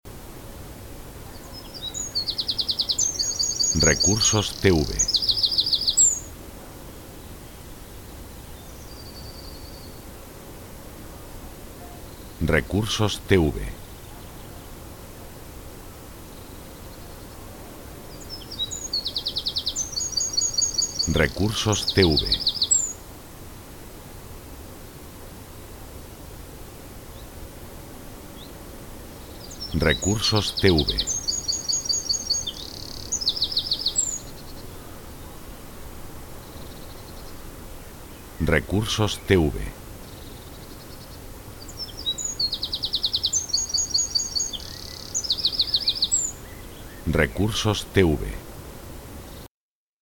pájaros cantando en la mañana de un día de primavera
bird_sings_in_the_afternoon.mp3